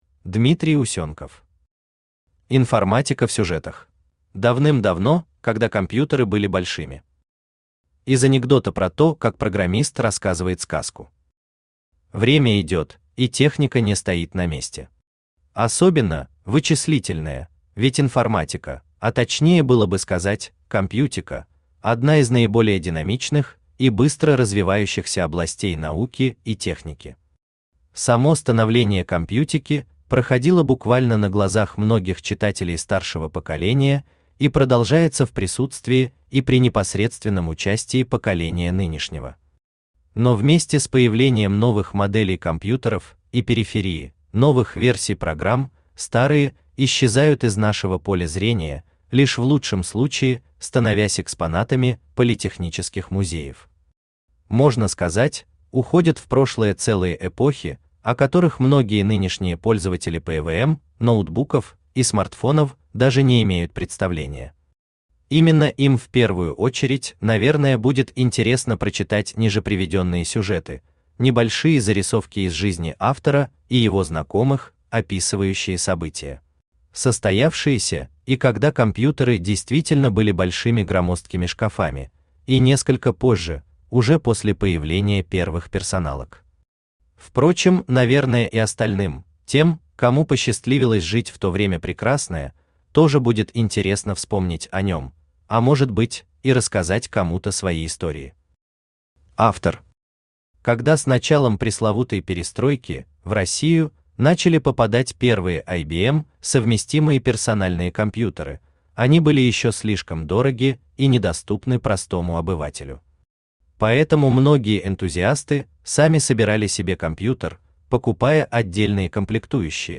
Aудиокнига Информатика в сюжетах Автор Дмитрий Юрьевич Усенков Читает аудиокнигу Авточтец ЛитРес.